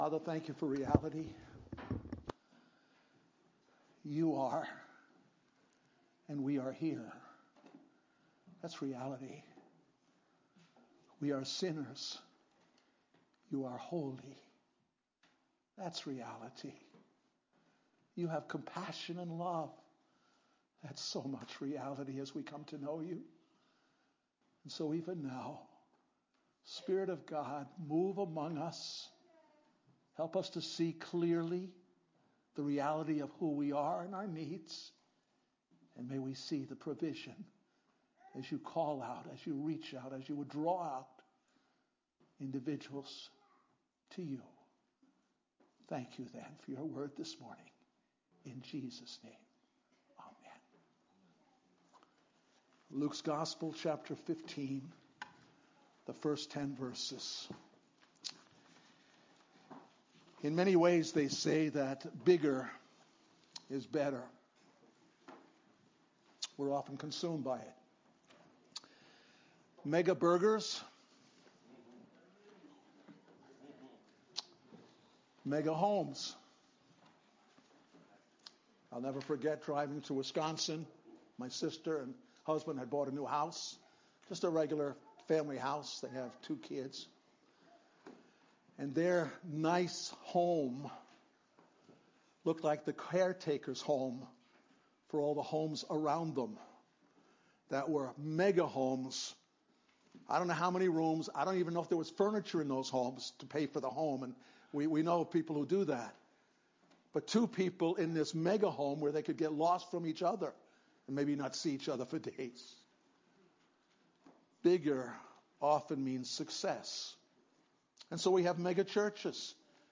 The sermon volume is a little low, but gets louder about 20 minutes in. We apologize for the technical difficulties.